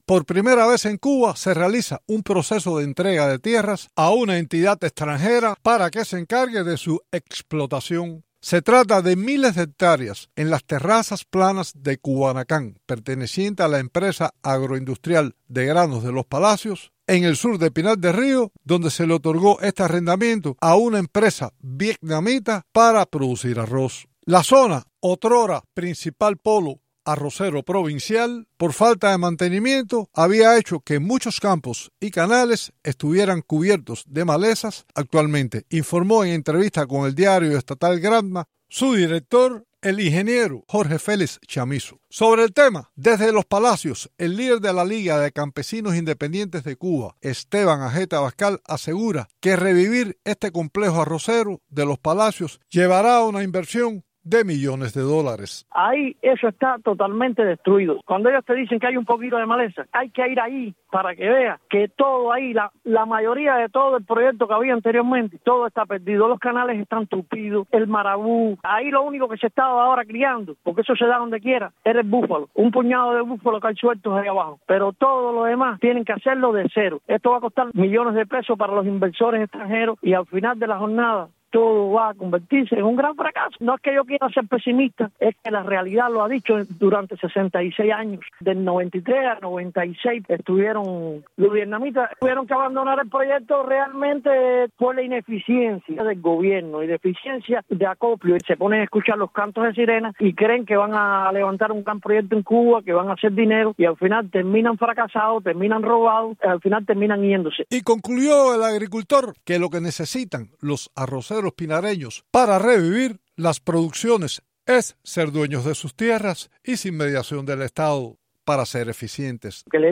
El campesino cubano